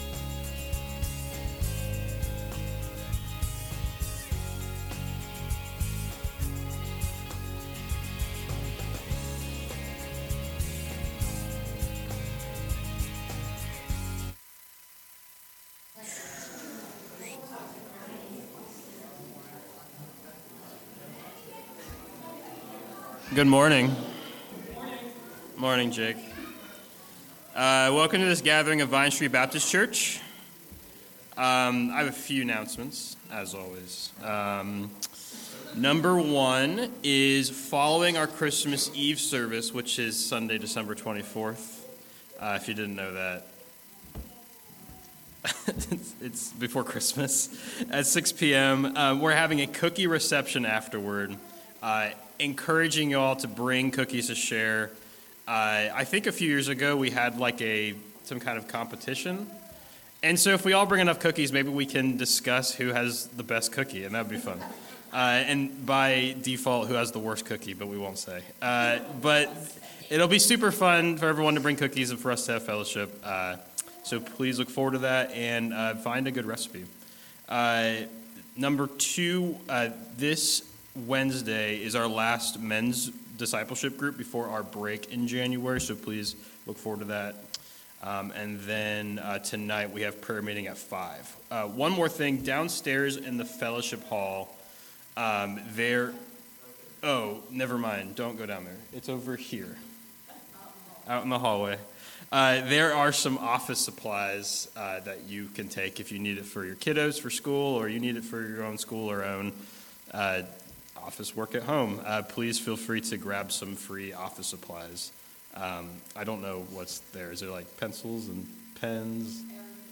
December 10 Worship Audio – Full Service